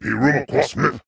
Sangheili voice clip from Halo: Reach.